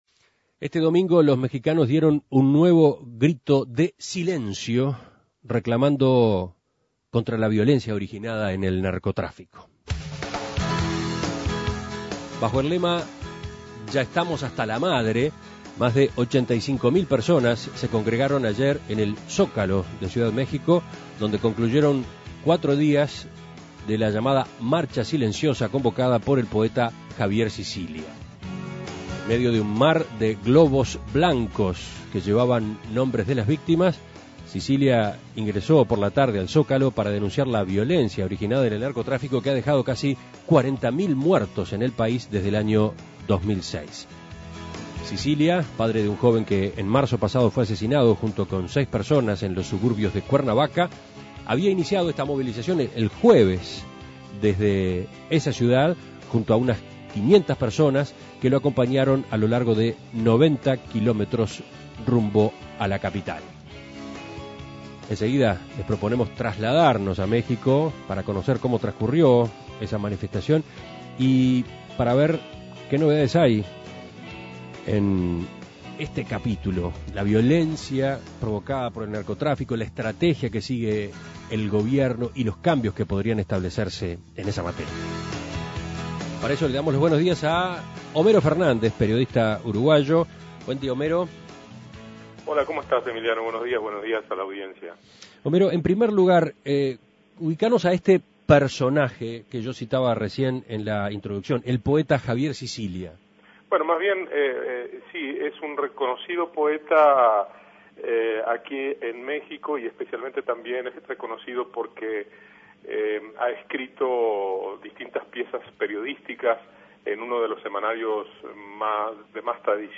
Escuche el contacto con el periodista uruguayo